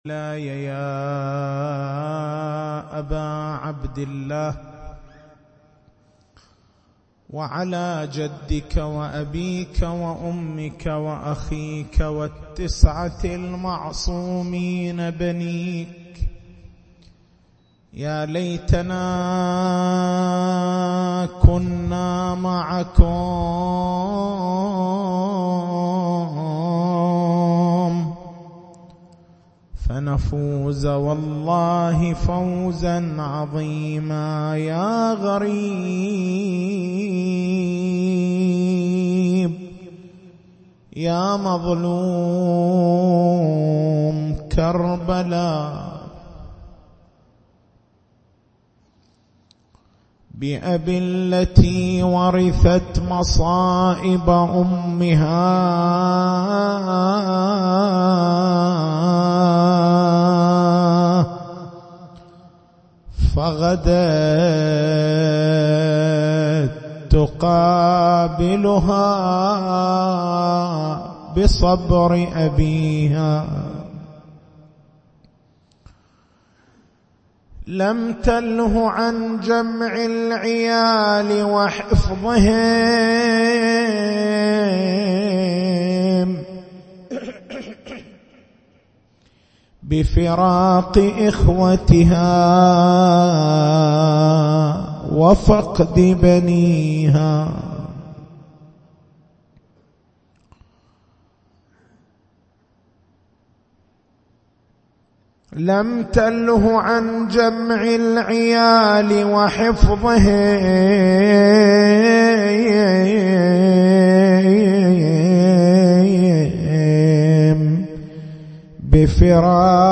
تاريخ المحاضرة: 11/09/1438 نقاط البحث: مغايرة الاسم للمسمّى الفرق بين الأسماء اللفظيّة والأسماء العينيّة تفاوت الأسماء في الدلالة على المسمّى ما هي علاقة أهل البيت (ع) بالاسم الأعظم؟